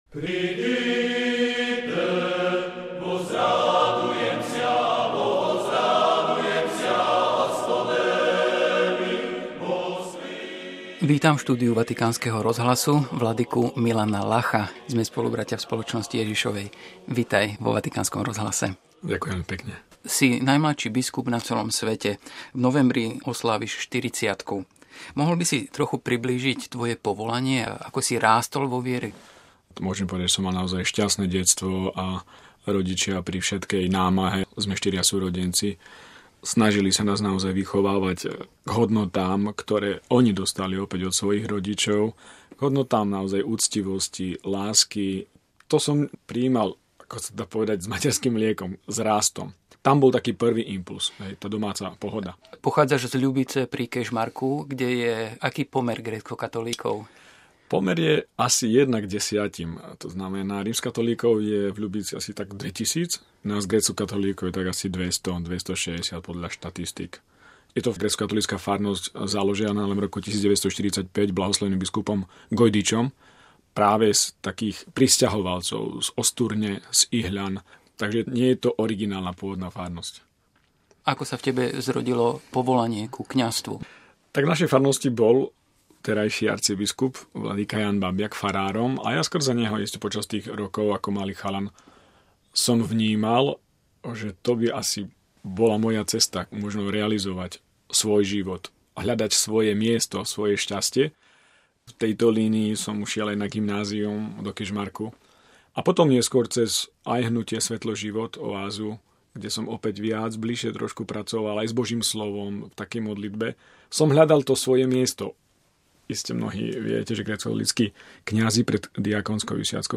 Rozhovor s vladykom Milanom Lachom SJ